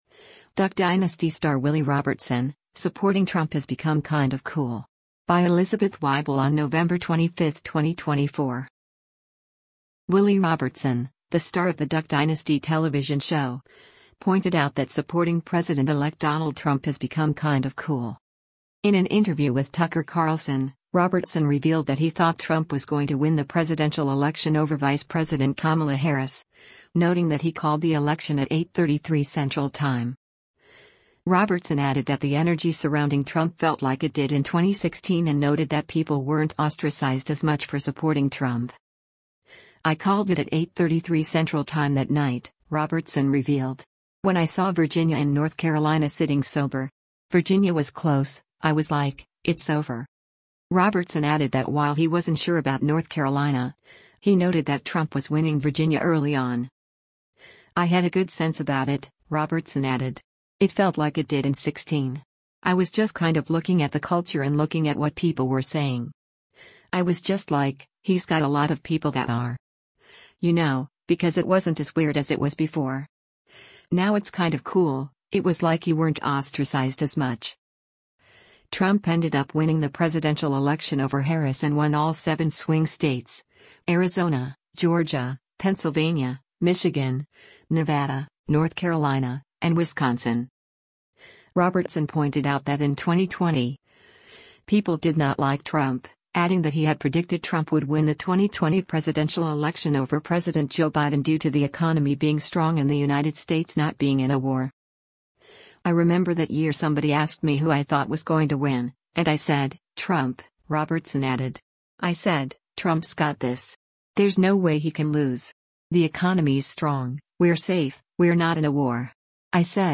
In an interview with Tucker Carlson, Robertson revealed that he thought Trump was going to win the presidential election over Vice President Kamala Harris, noting that he called the election at “8:33 Central Time.”